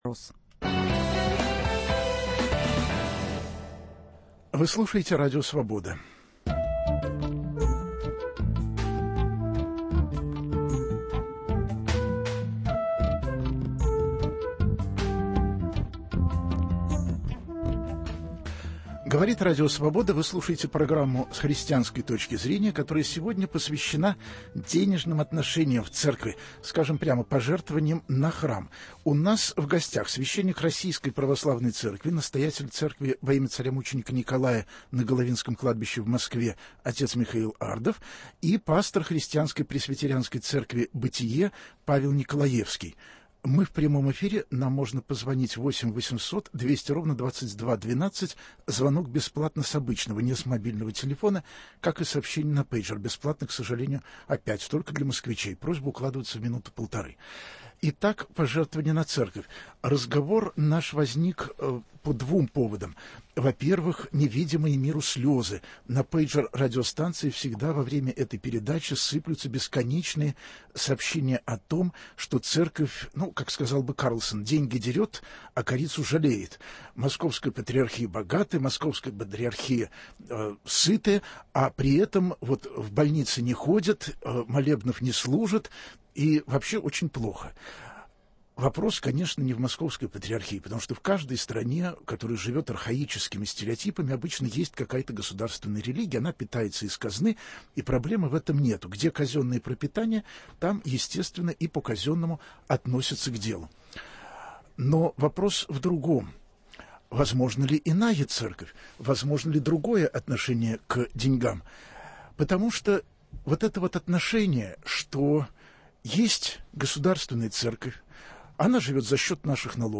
Многие люди в претензии к церкви, что она богатая, а о них не заботится. В передаче же примут участие священники и пасторы тех православных и протестантских конфессий, которые не входят в государственный "пул", получают одни синяки и шишки, - они-то как выживают? Возможно ли веровать без государственных дотаций и сколько придётся выкладывать за такую веру?